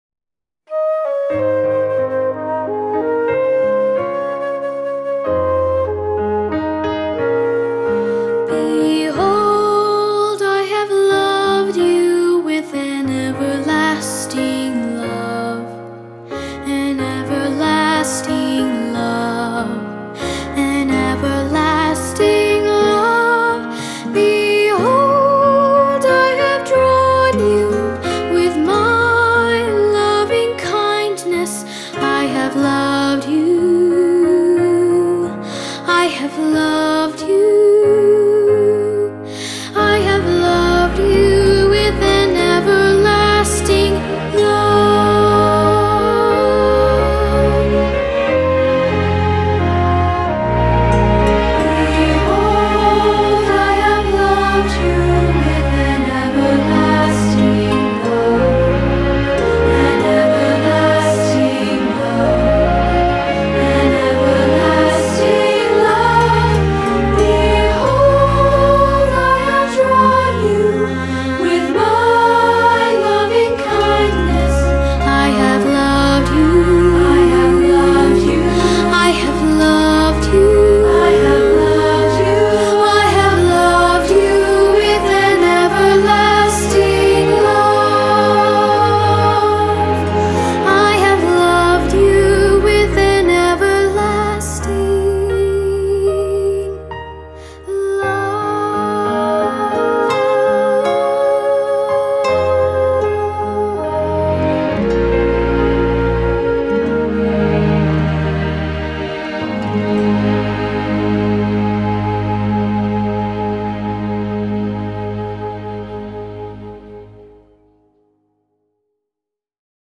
Unison/Two-part with piano